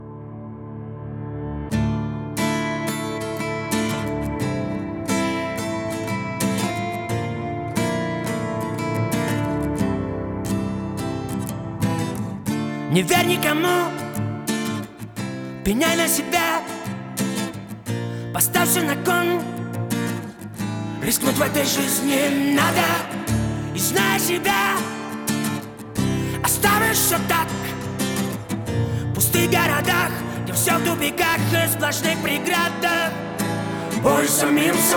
Жанр: Русская поп-музыка / Поп / Русские
# Russian Pop